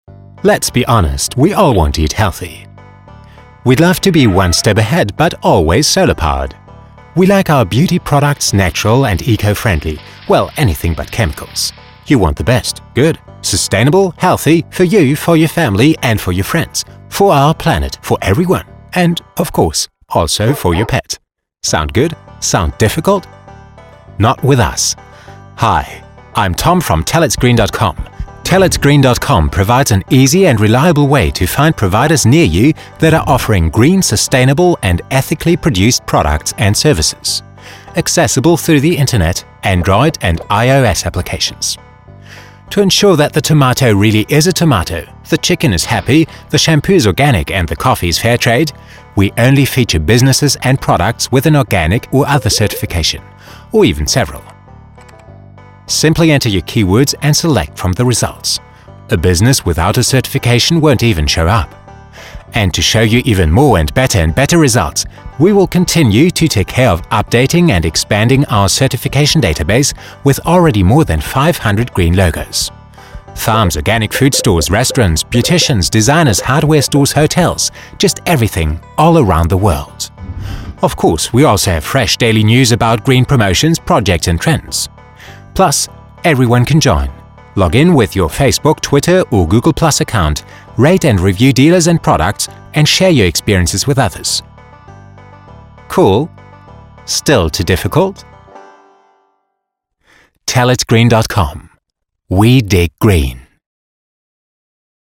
Sehr angenehme, tiefe, warme Stimme, äußerst variabel.
Mein Englisch ist sehr neutral (näher am Britischen als am Amerikanischen) und wird gerne für internationale Produktionenen eingesetzt.
Sprechprobe: Sonstiges (Muttersprache):
English voice over artist with recording studio
Imagefilm animiert (englisch).mp3